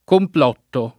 [ kompl 0 tto ]